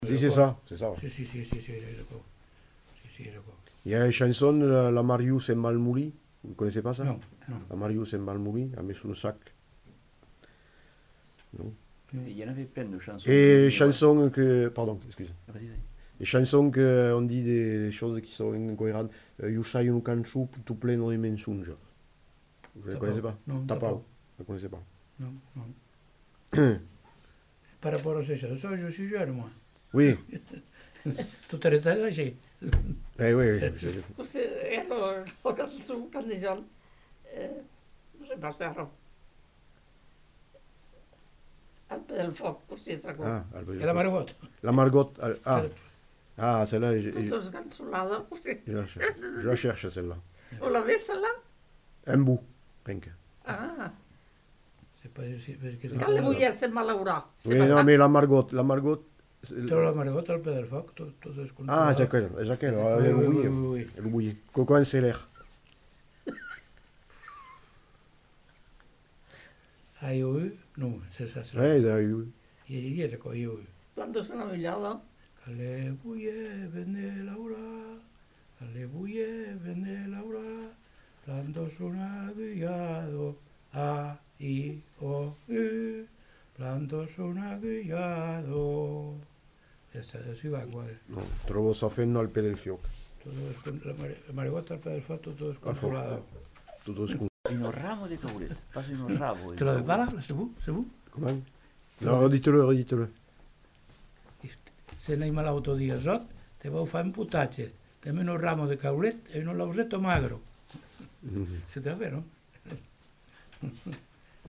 Aire culturelle : Lauragais
Genre : chant
Effectif : 1
Type de voix : voix d'homme
Production du son : chanté
Notes consultables : Précédé d'évocations d'autres chansons.